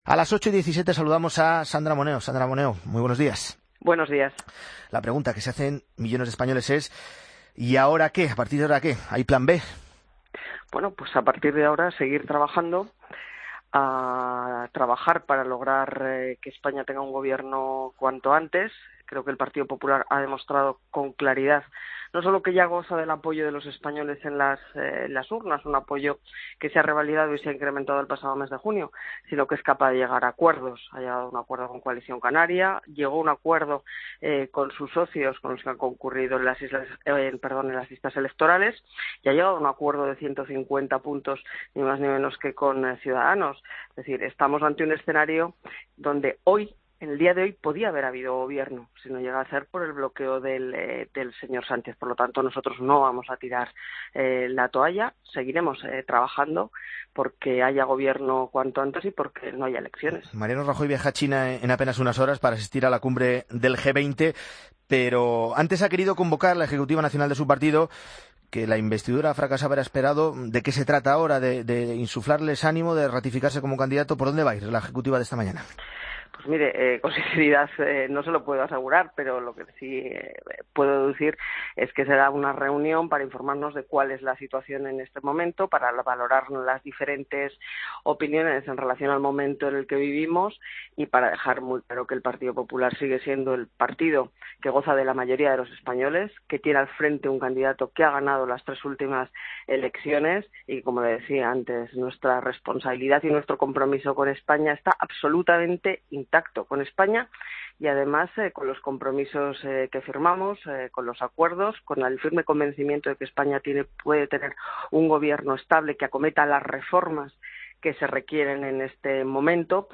Entrevista a Sandra Moneo, diputada del PP